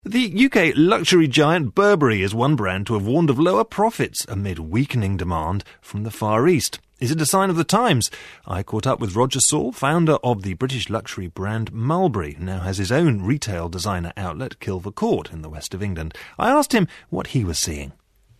【英音模仿秀】风水轮流转 时尚也长腿 听力文件下载—在线英语听力室